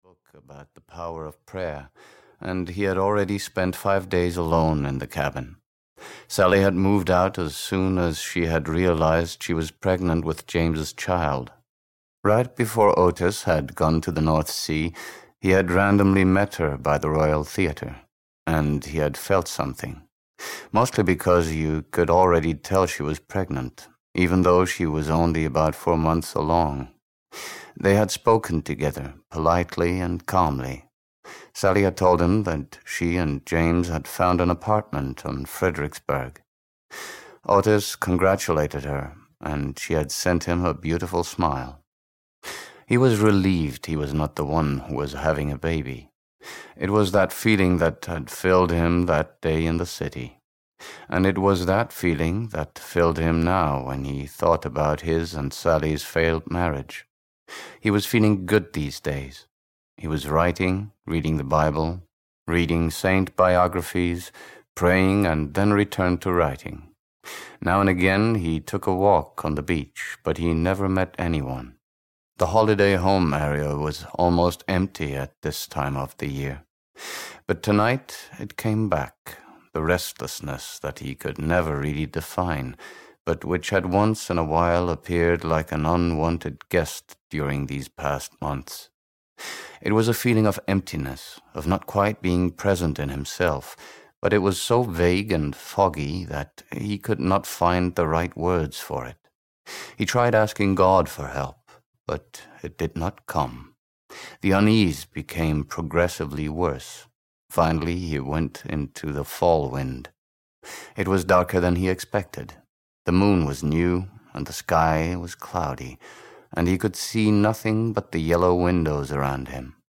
Cabin Fever 2: Forbidden Fruit (EN) audiokniha
Ukázka z knihy